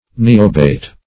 niobate - definition of niobate - synonyms, pronunciation, spelling from Free Dictionary Search Result for " niobate" : The Collaborative International Dictionary of English v.0.48: Niobate \Ni"o*bate\, n. [See Niobium .]